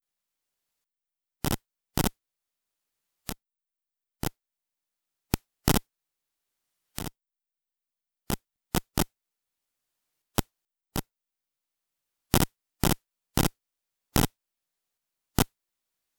Transmissions originating from a Zigbee coordinator, repeated by Zigbee Routers (mesh devices)